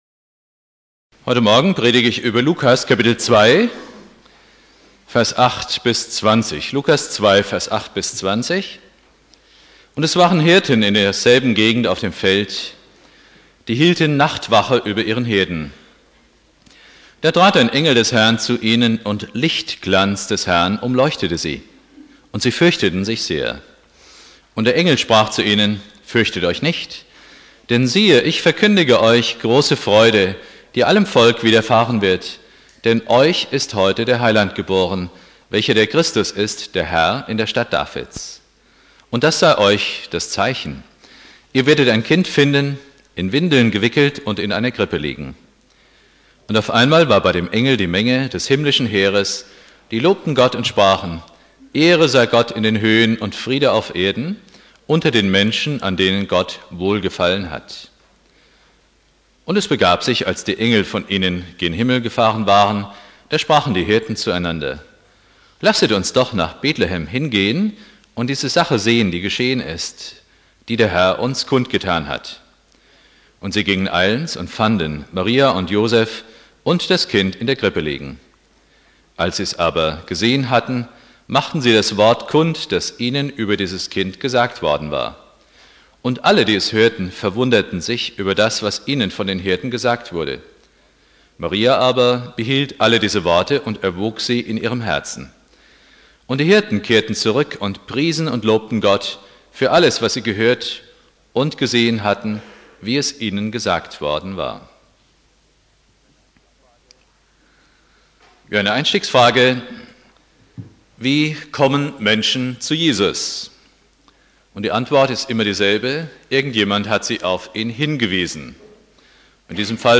Predigt
1.Weihnachtstag Prediger